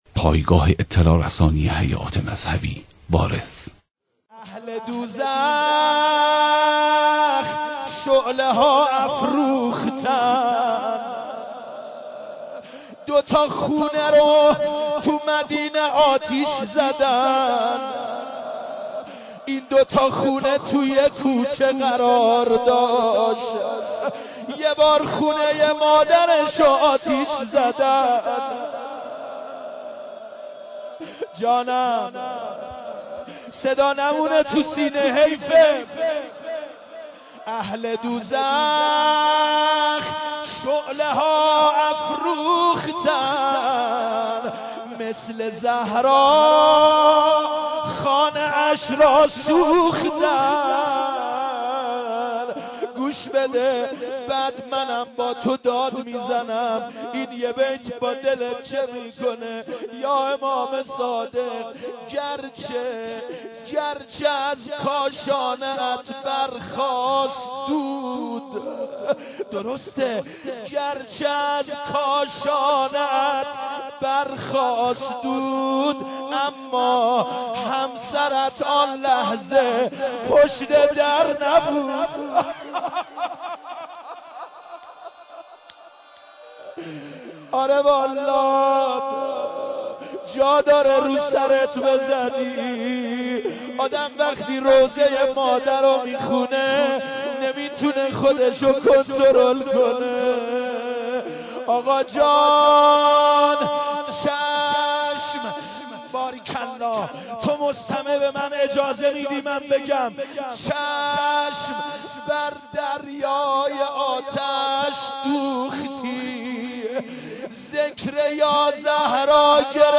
مداحی
به مناسبت شهادت امام صادق (ع)